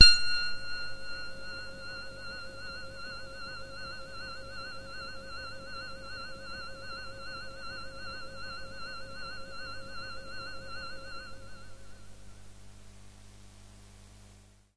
描述：这是我的Q Rack硬件合成器的一个样本。
低通滤波器使声音变得圆润而柔和。
在较高的区域，声音变得非常柔和，在归一化之后，一些噪音变得很明显。
Tag: 低音 电子 醇厚 多样品 柔软 合成器 华尔